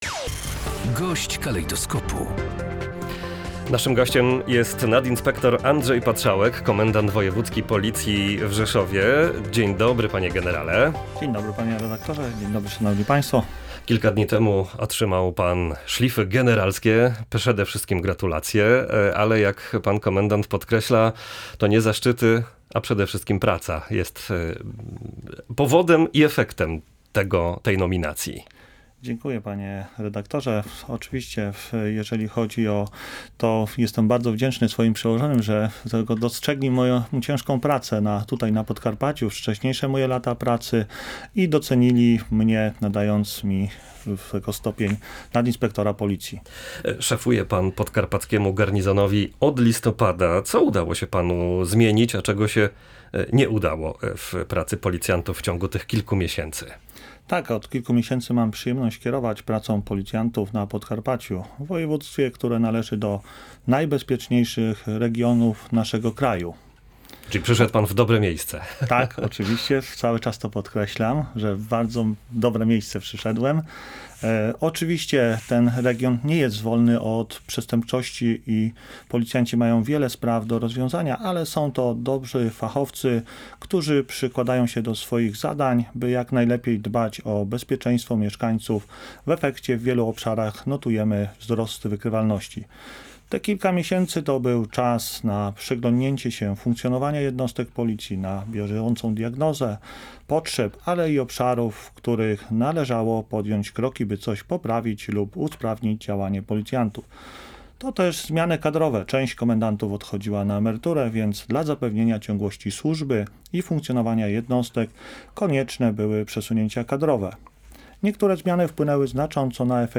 rozmowa-1.mp3